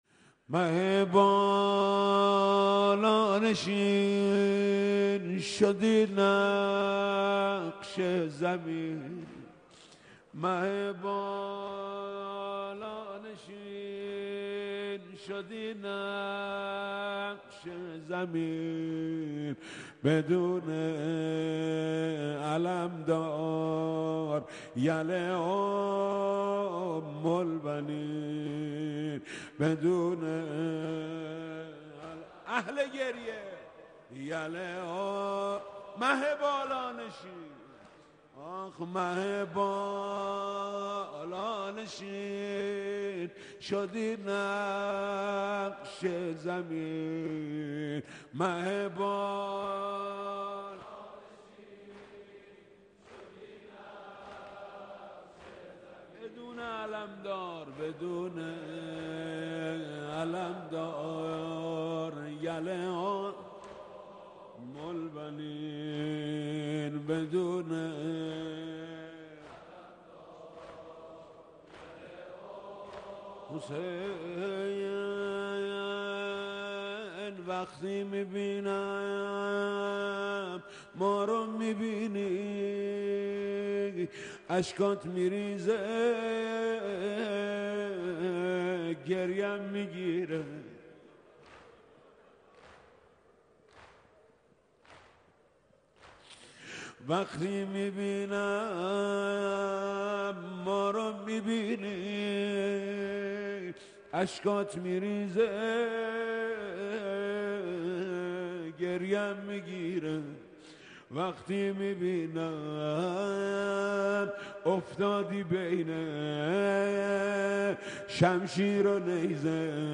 مداحی جدید حاج محمود کریمی دوم رمضان حرم امام رضا (ع) سه شنبه 17 اردیبهشت 1398